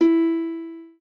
lyre_e.ogg